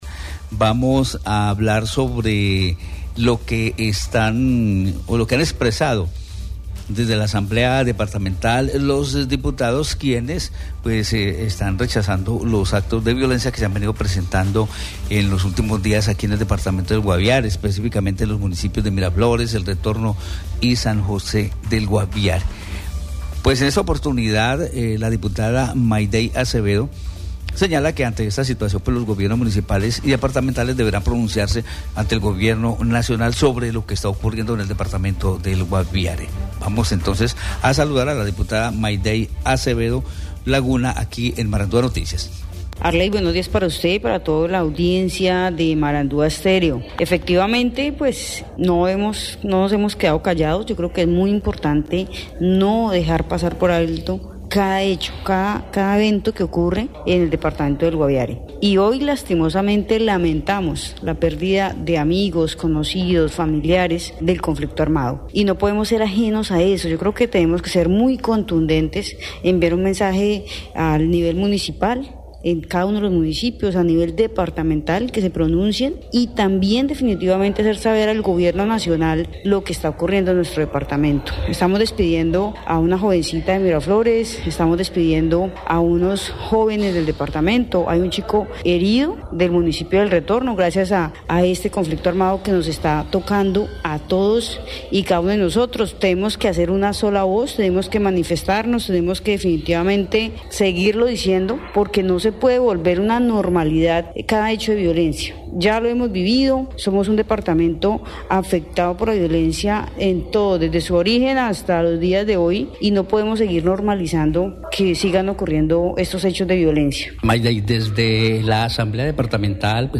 La diputada Maidey Acevedo Laguna, en entrevista con Marandua Noticias ha señalado que ante esta situación las administraciones municipales y del departamento, deberán pronunciarse ante el Gobierno Nacional sobre lo que ocurre en el Guaviare de manera recurrente.